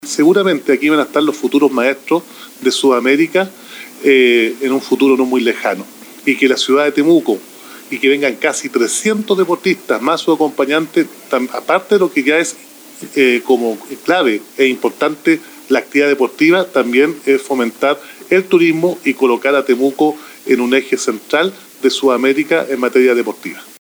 La ceremonia inaugural se realizó esta mañana en el Polideportivo Rufino Bernedo en el Campo de Deportes Ñielol con la participación del alcalde de Temuco, Roberto Neira, y representantes del deporte nacional y regional.
Roberto-Neira-alcalde-de-Temuco.mp3